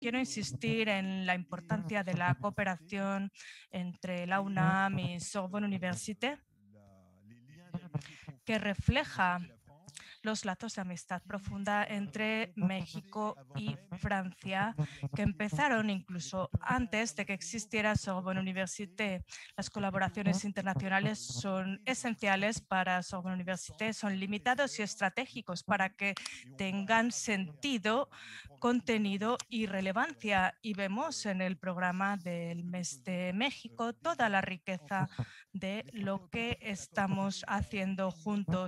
(traducción)